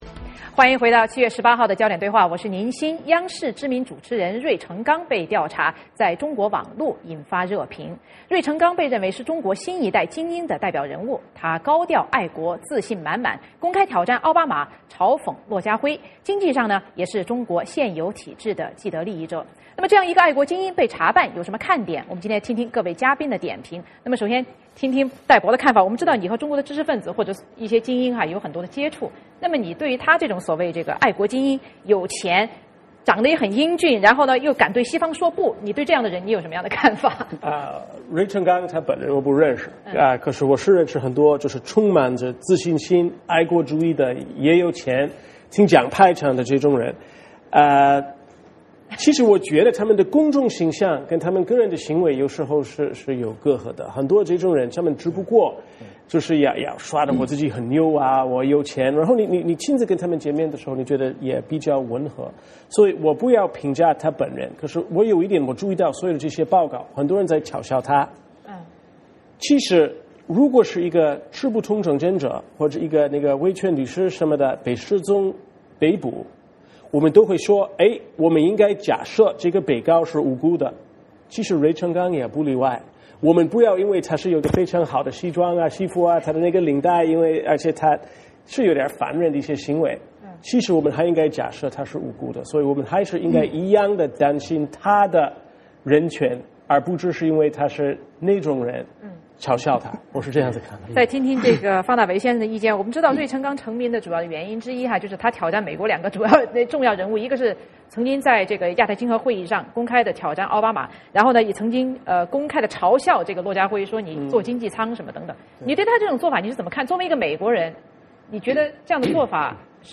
我们来听听今天嘉宾的点评。